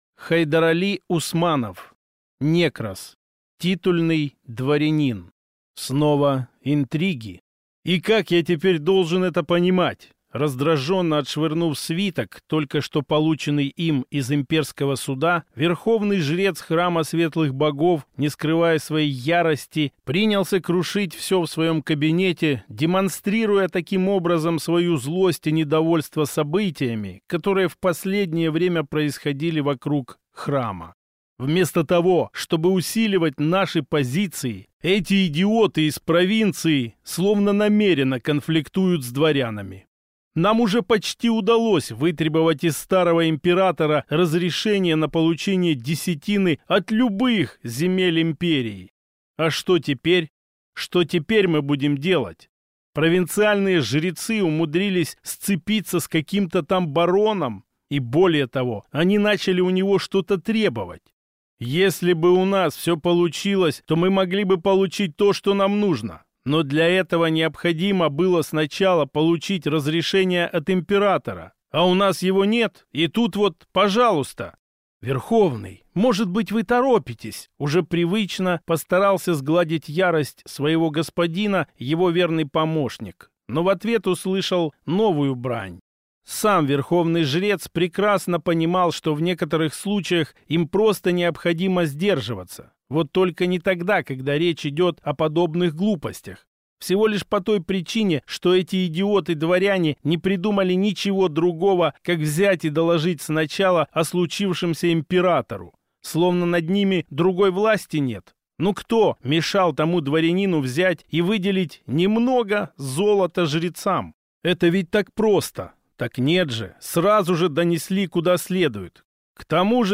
Аудиокнига Некрос. Титульный дворянин | Библиотека аудиокниг
Прослушать и бесплатно скачать фрагмент аудиокниги